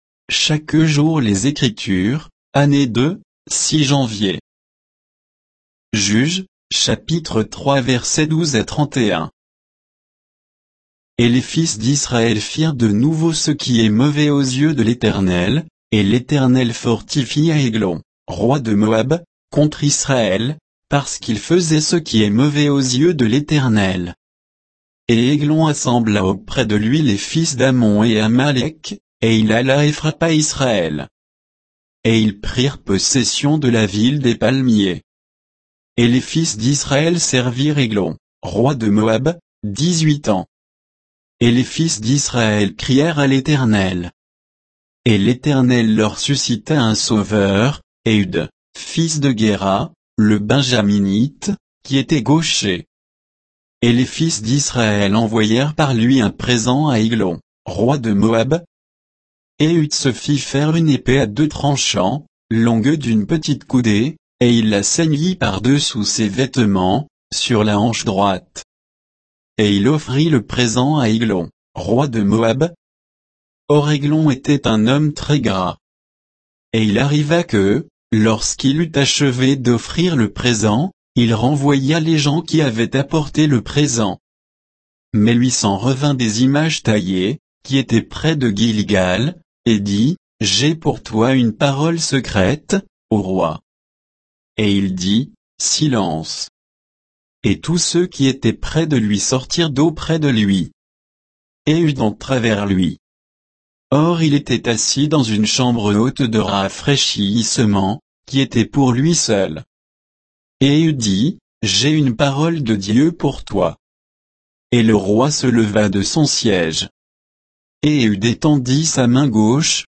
Méditation quoditienne de Chaque jour les Écritures sur Juges 3